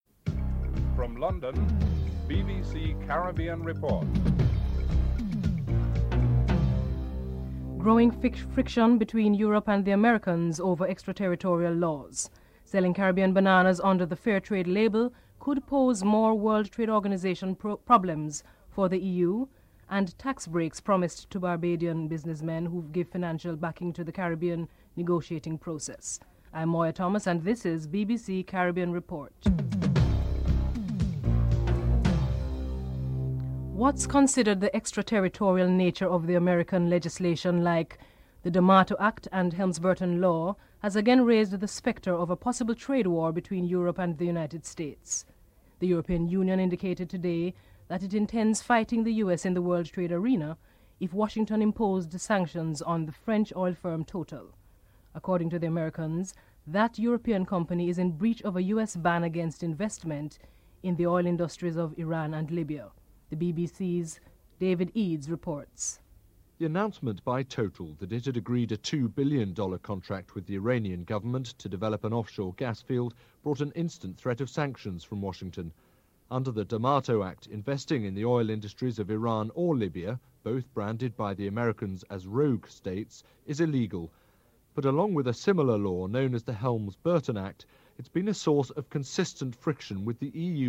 1. Headlines (00:00-00:33)